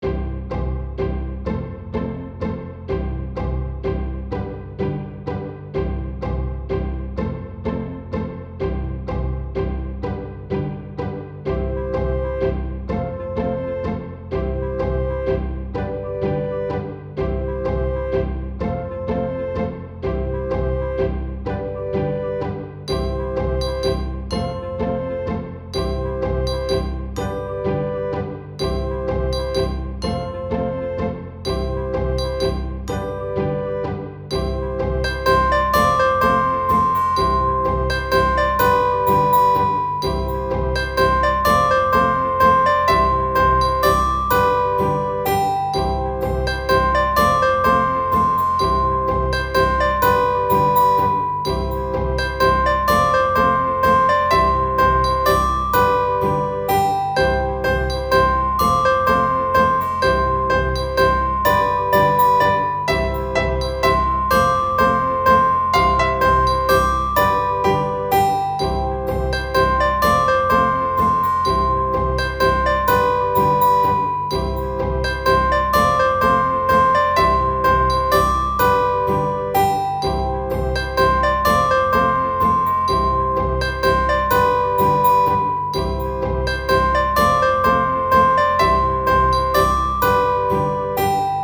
Pretty Maiden - medieval/fantasy game cheerful opening